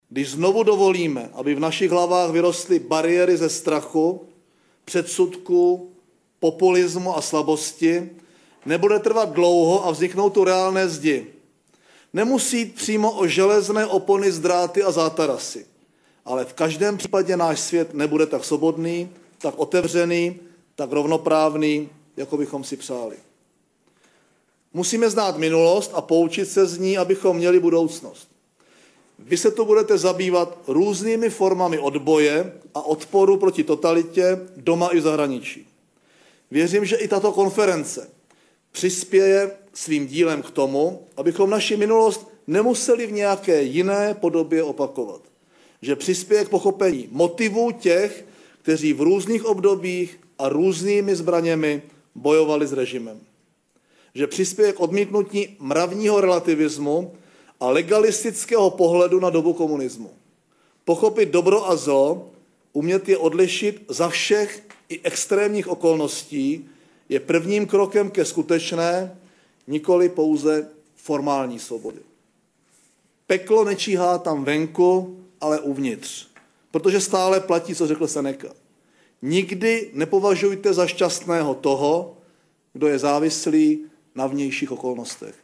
Konferenci zahájil úvodním proslovem odstupující předseda vlády Mirek Topolánek.